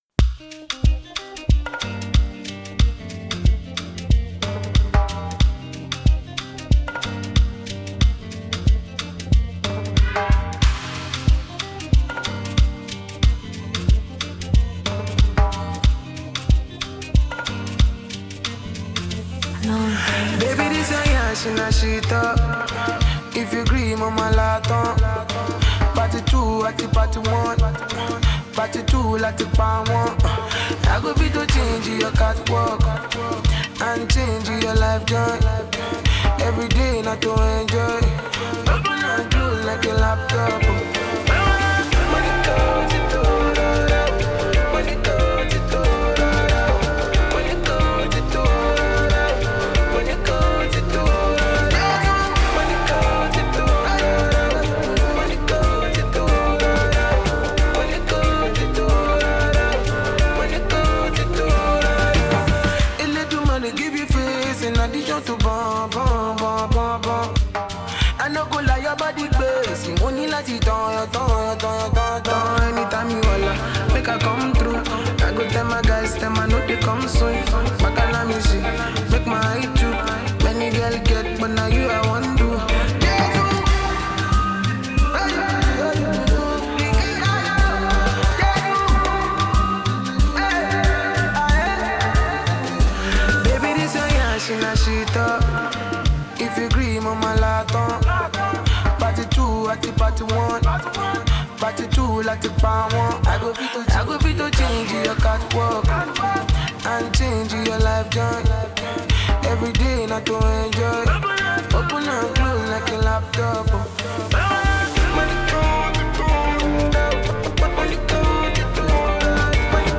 The Afro-beat and jazz influenced sound